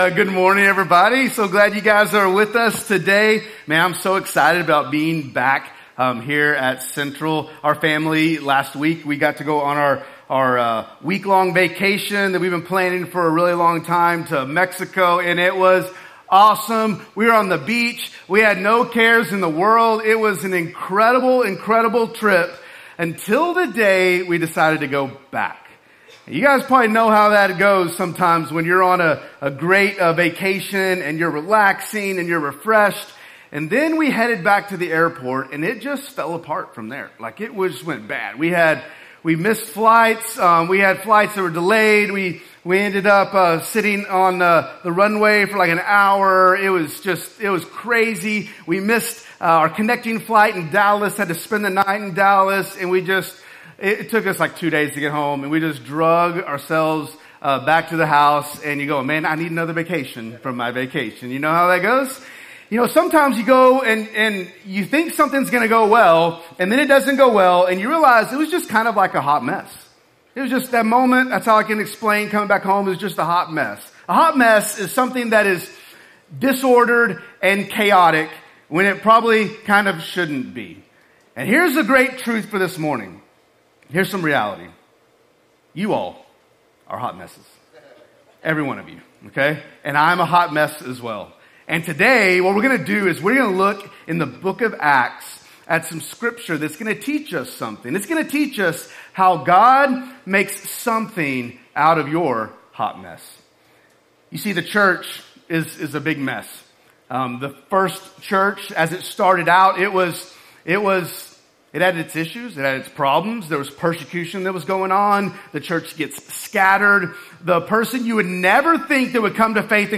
Sermons | Central Baptist Church Owasso
"So They May Hear," our summer sermon series deep diving into the book of Acts